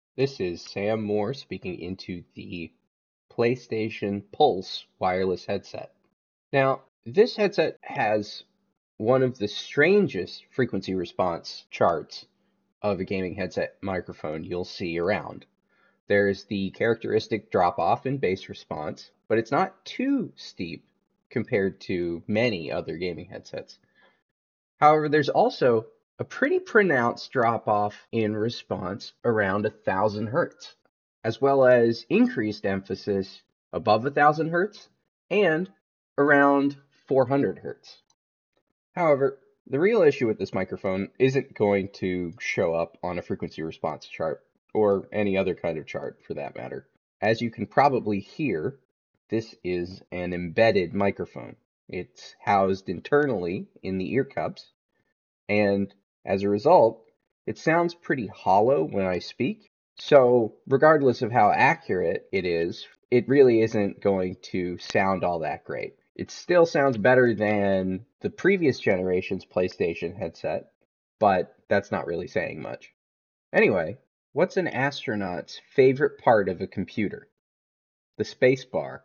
PlayStation-Pulse-3D-Wireless-Headset-Mic-sample.mp3